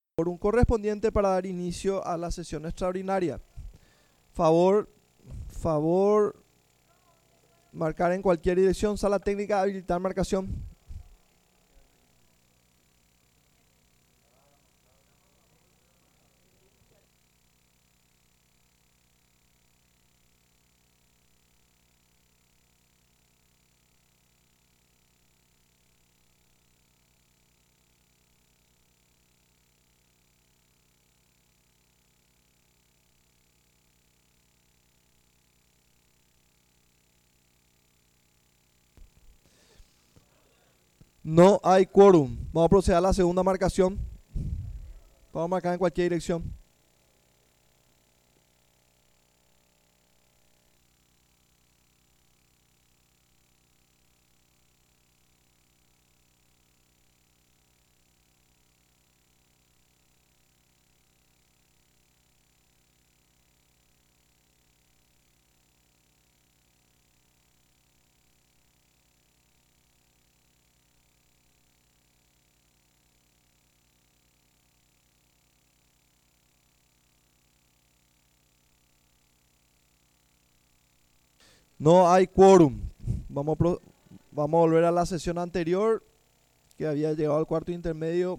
Sesión Extraordinaria – Segunda Sesión, 31 de marzo de 2026